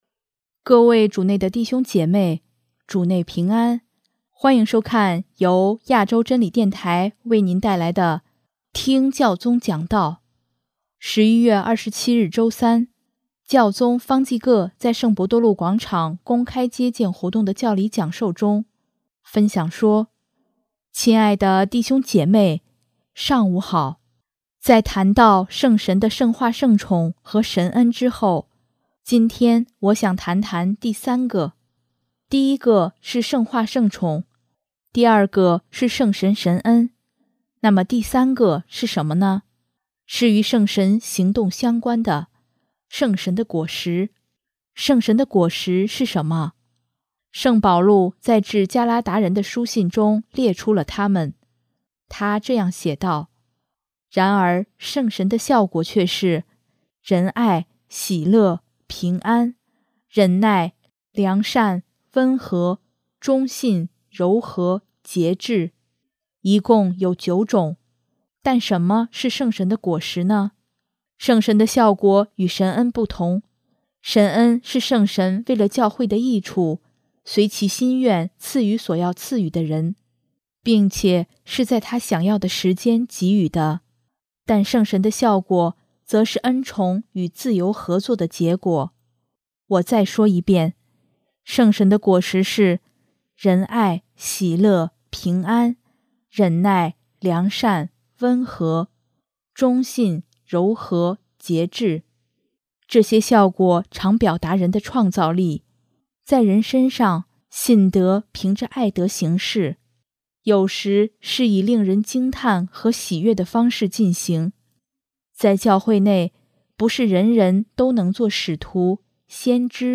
11月27日周三，教宗方济各在圣伯多禄广场公开接见活动的教理讲授中，分享说：